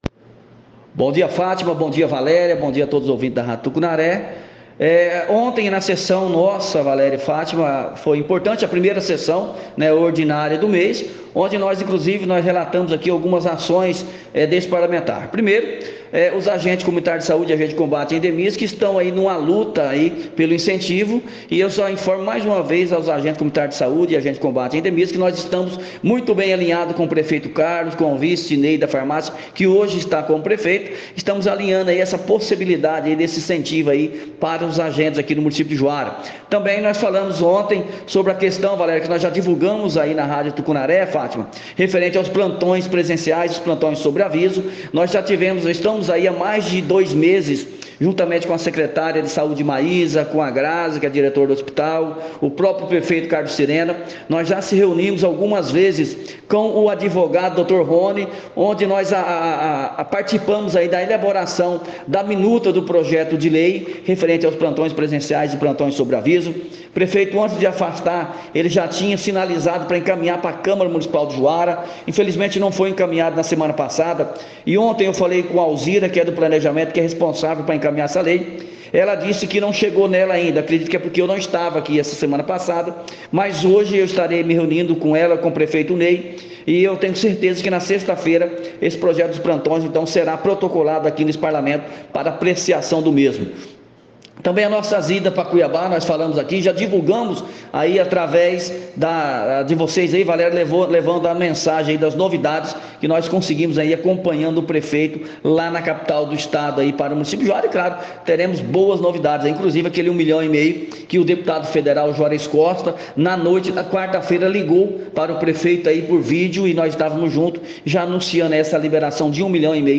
A primeira sessão ordinária da Câmara de Vereadores de Juara de 2023 aconteceu nesse dia 06 e após o término, alguns vereadores concederam entrevista à Rádio Tucunaré para falarem sobre suas ações.